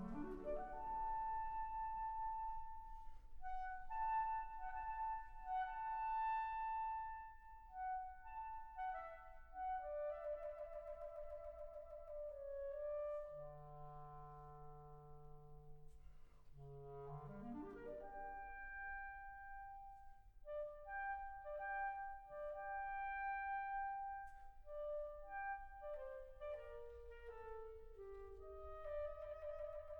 Neeme Järvi et l'Orchestre de la Suisse Romande explorent la musique de Jacques Offenbach avec un parcours sur l'oeuvre orchestrale du compositeur.
0 => "Musique orchestrale"